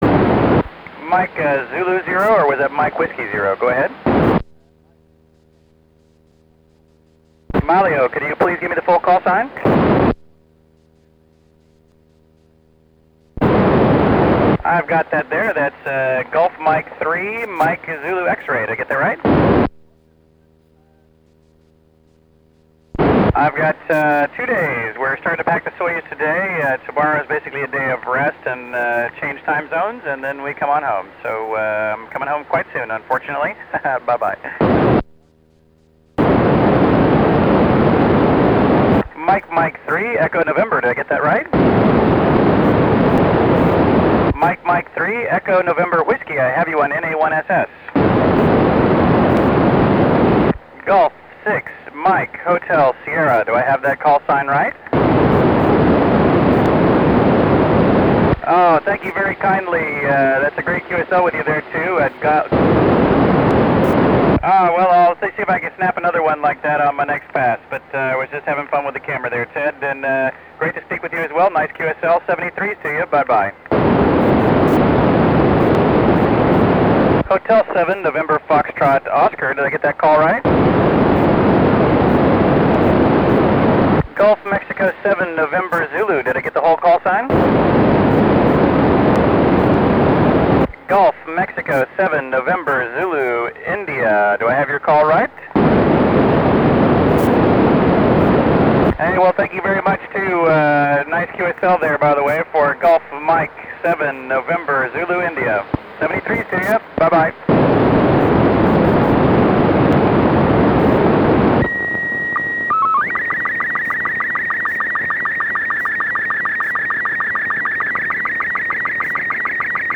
QSO WITH NA1SS - UK PASS with SSTV
A SSTV picture was sent at the end.